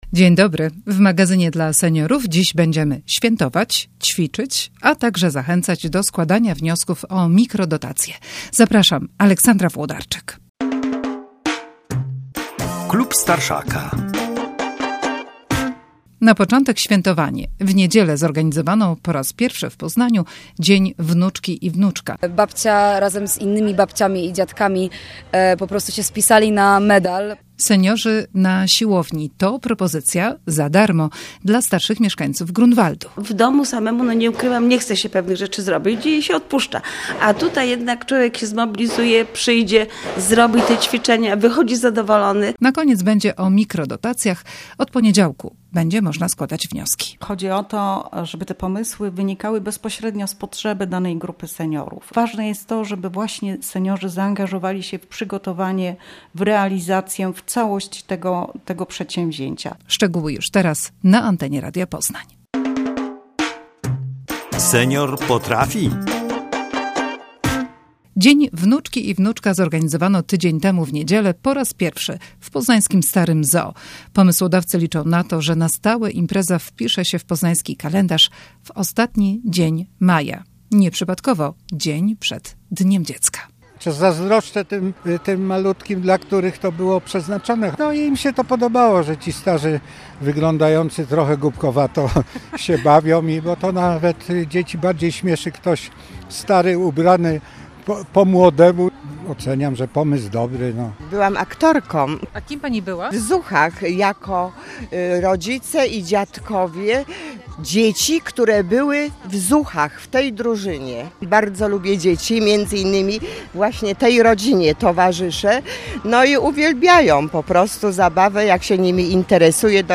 Relacja z organizowanego po raz pierwszy w Poznaniu Dnia Wnuczki i Wnuczka - impreza odbyła się w Starym ZOO w Poznaniu